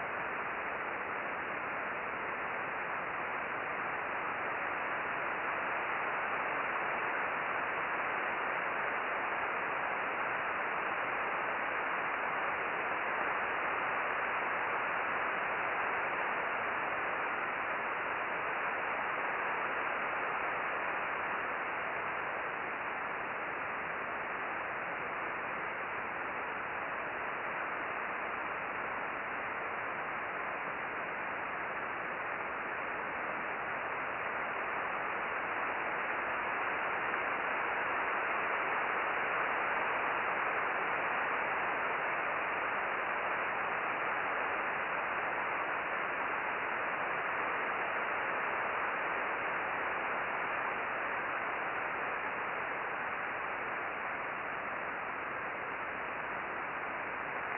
Solar Radio Observations for 03 June 2012
The solar burst shown in the above chart was preceded by a burst of RFI at 19.5 MHz.